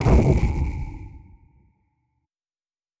pgs/Assets/Audio/Sci-Fi Sounds/Weapons/Sci Fi Explosion 01.wav at master
Sci Fi Explosion 01.wav